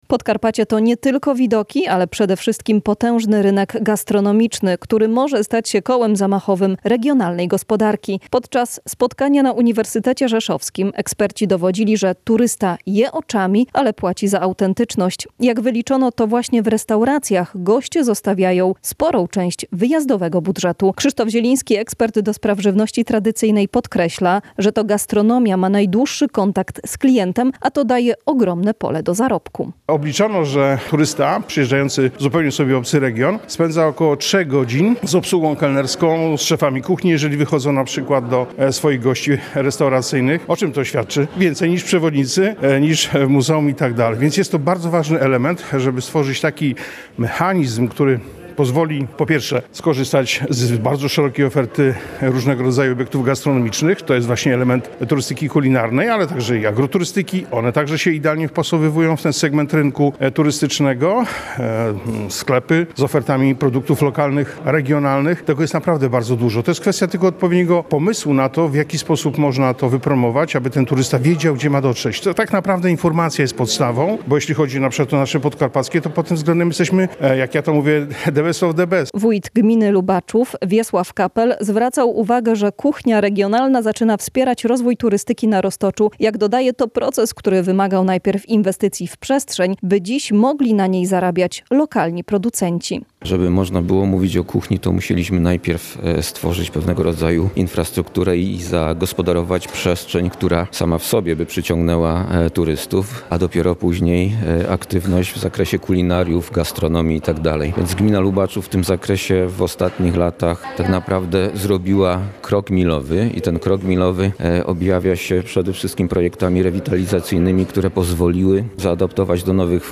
Posłuchaj relacji z II Światowego dnia Turystyki Kulinarnej na UR.